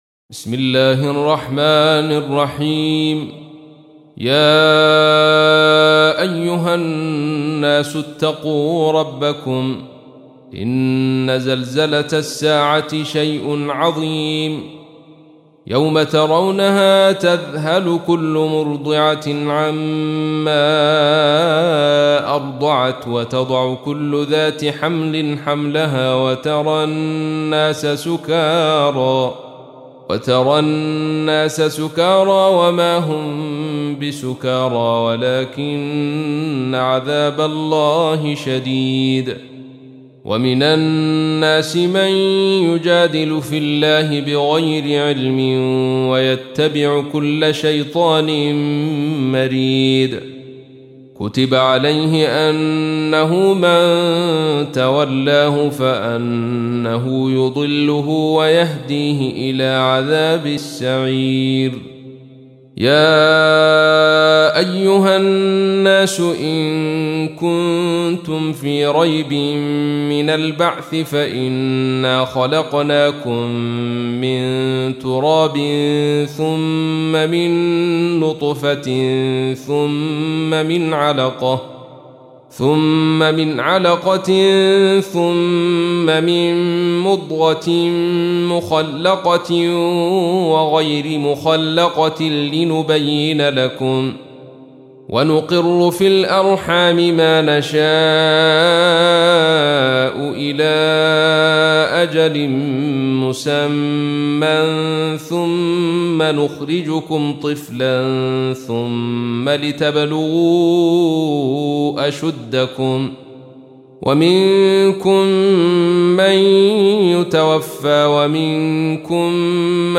تحميل : 22. سورة الحج / القارئ عبد الرشيد صوفي / القرآن الكريم / موقع يا حسين